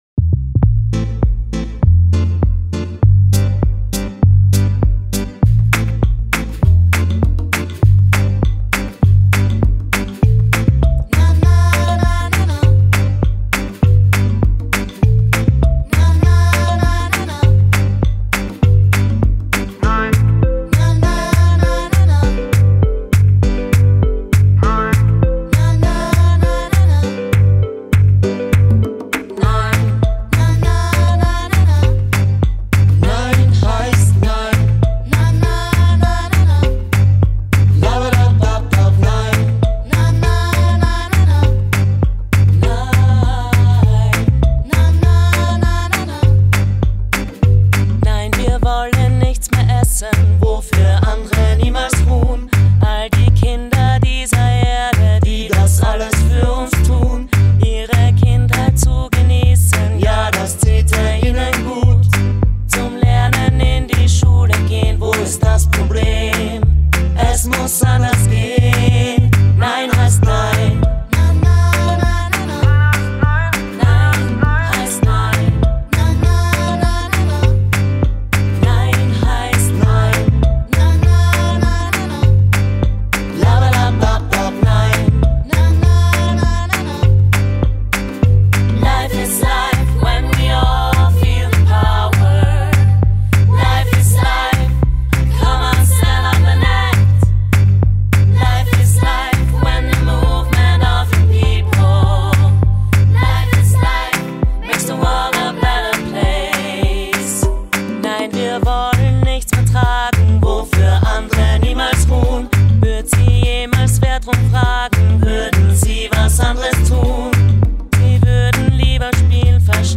Mit einem mitreißenden Song – einer Coverversion